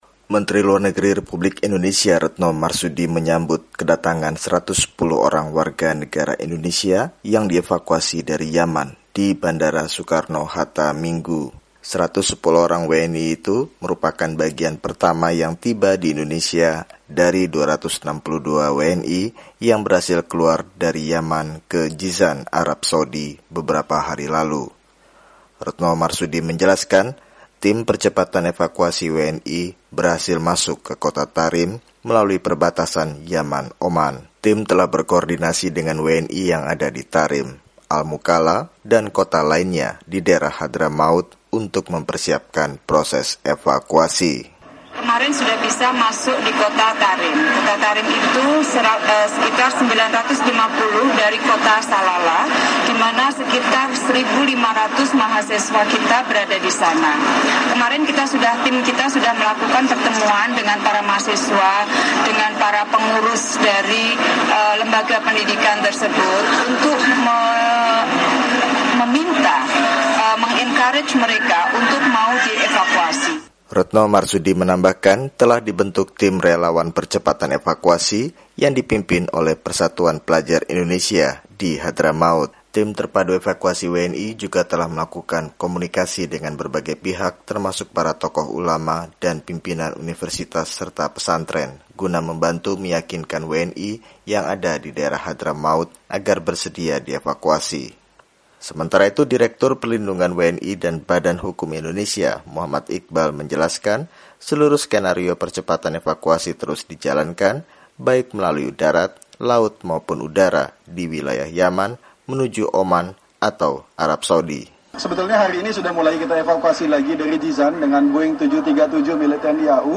Menlu Retno Marsudi berbincang dengan sejumlah WNI yang berhasil dievakuasi dari Yaman, di Bandara Soekarno Hatta, Minggu, 5 April 2015.